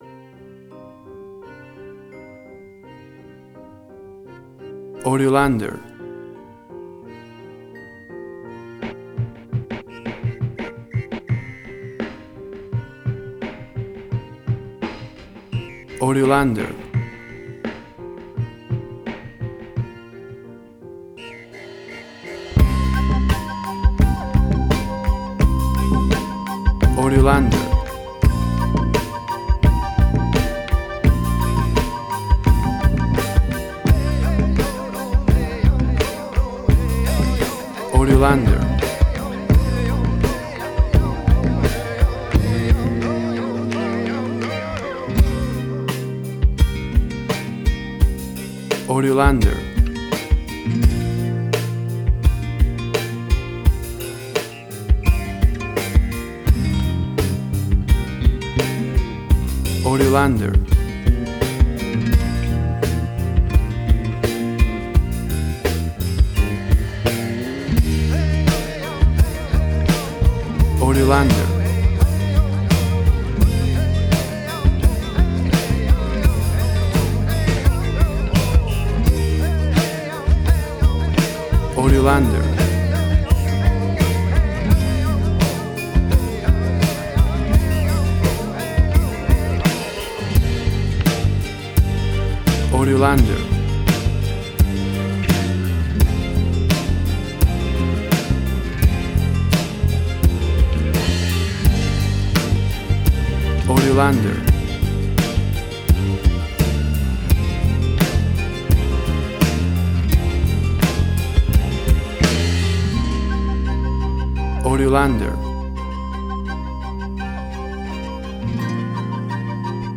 WAV Sample Rate: 24-Bit stereo, 48.0 kHz
Tempo (BPM): 85